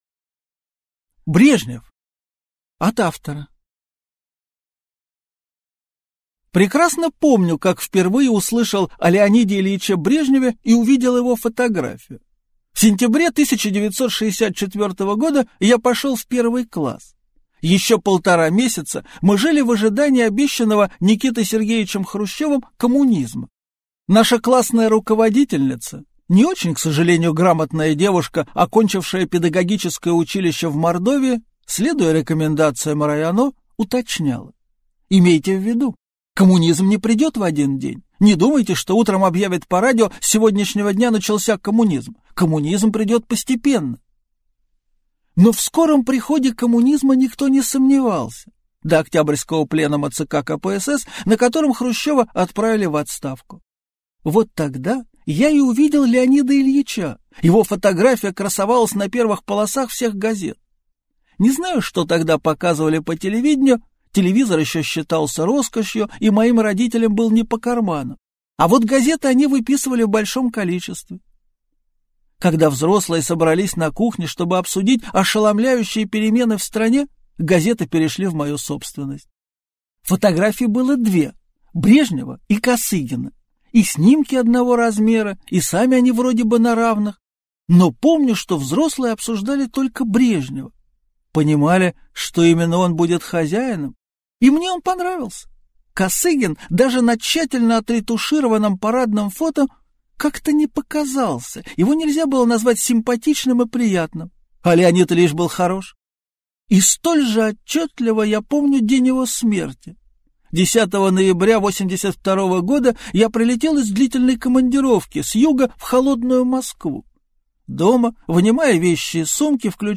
Аудиокнига Брежнев | Библиотека аудиокниг
Aудиокнига Брежнев Автор Леонид Млечин Читает аудиокнигу Леонид Млечин.